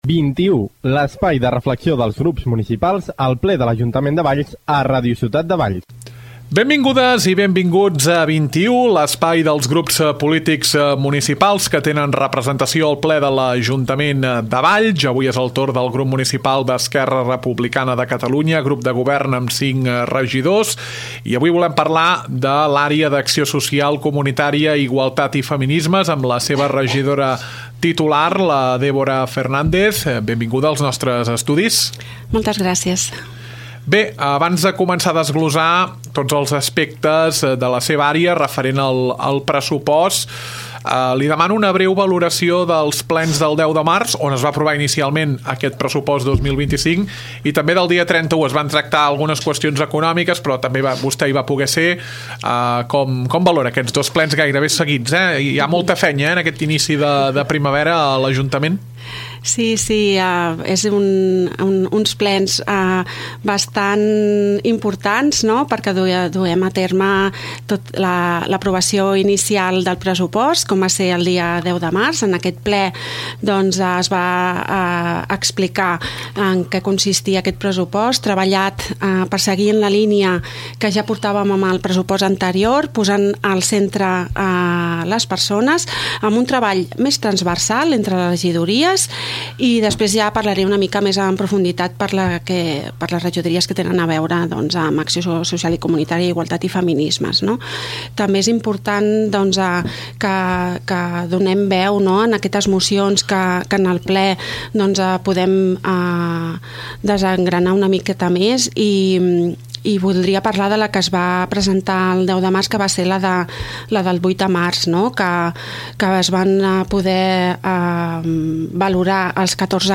Entrevista a Dèbora Fernández, regidora d’Acció Social i Comunitària i Igualtat i Feminismes.